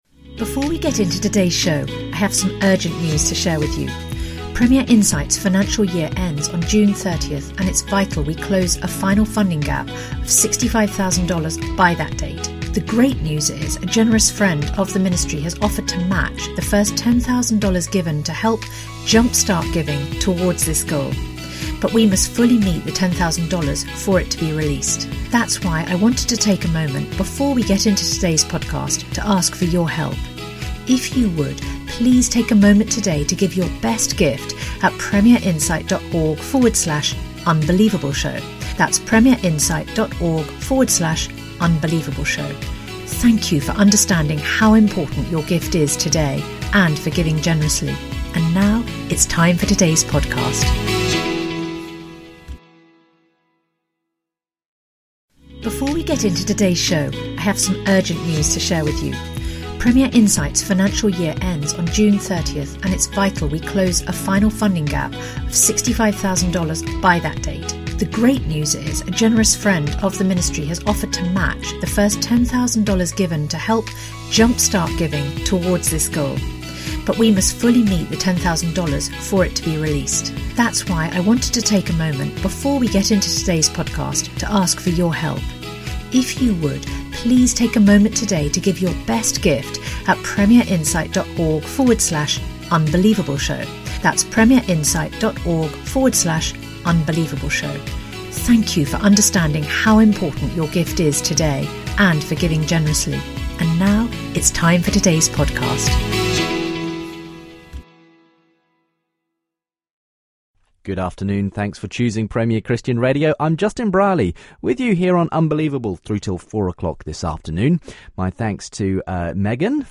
Also includes listener feedback on the last two weeks of programming.